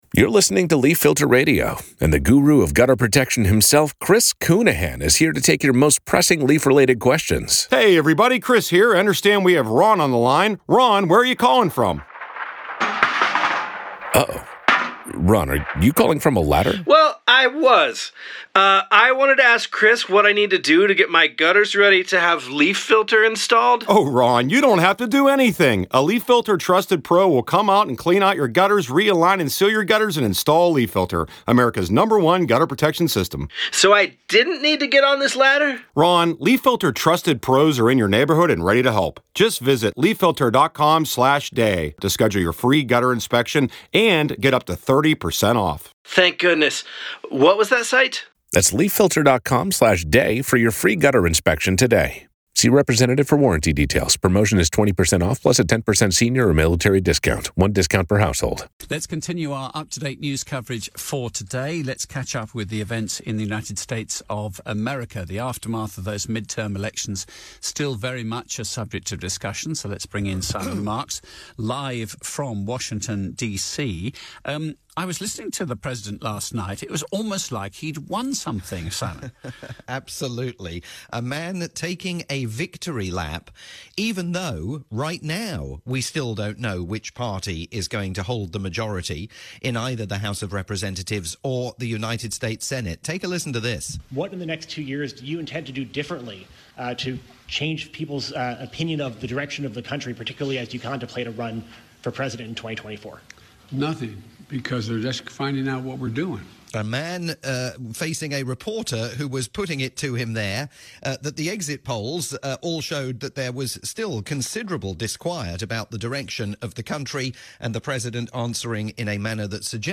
live report for LBC News in the UK